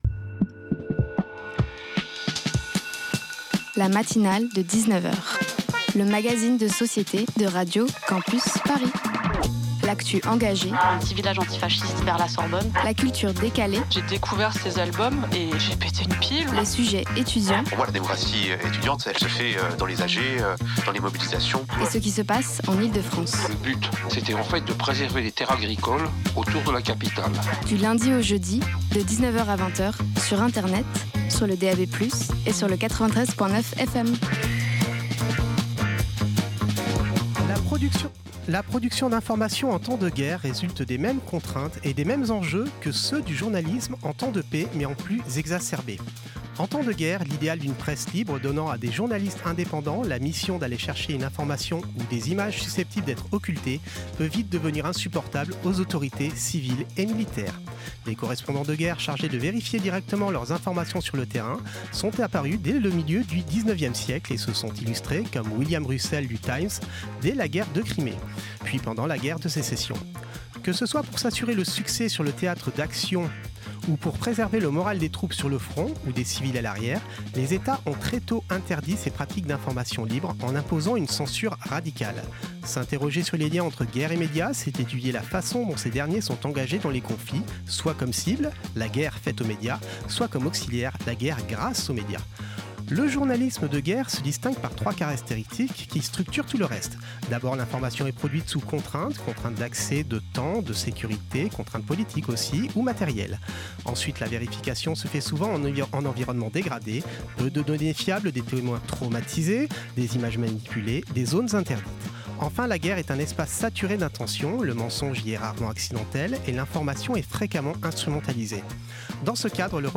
Enjeux du journalisme de guerre avec la revue Kometa & Festival Nouvelles Ondes Partager Type Magazine Société Culture jeudi 26 mars 2026 Lire Pause Télécharger Ce soir